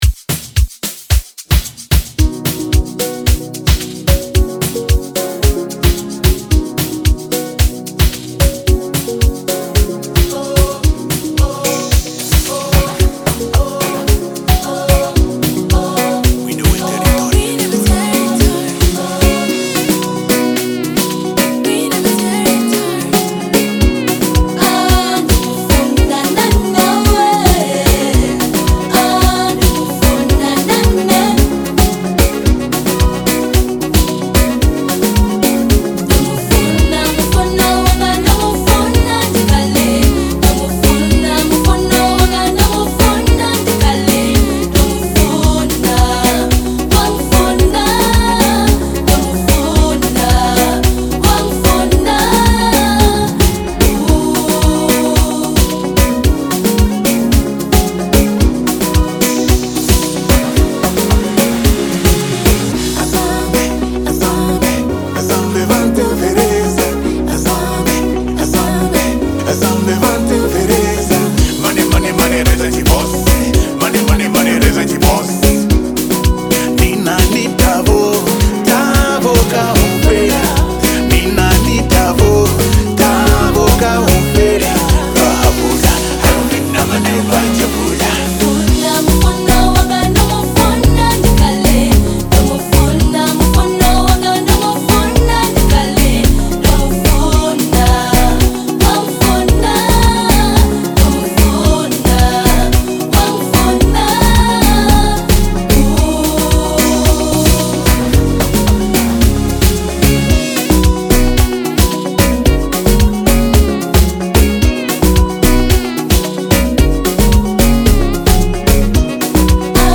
Categoria: Amapiano